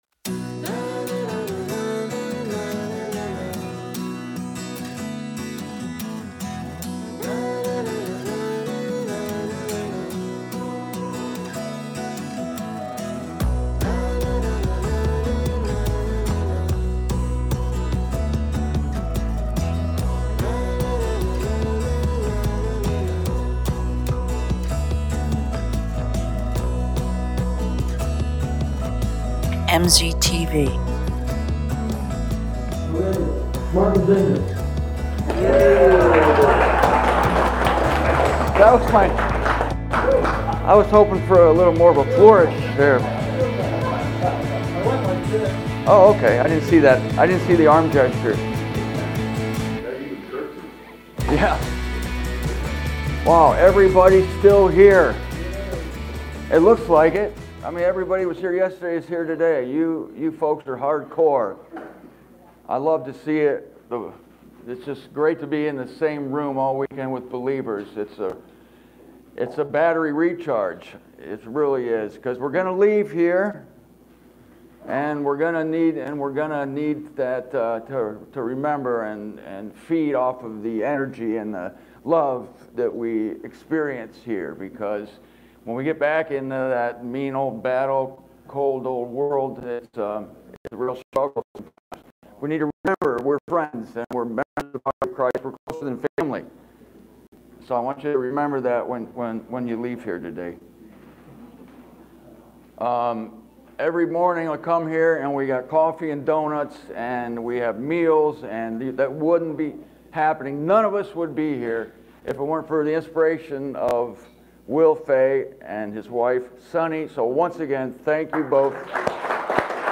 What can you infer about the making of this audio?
This was the first talk I gave on Sunday at the Nebraska conference last September.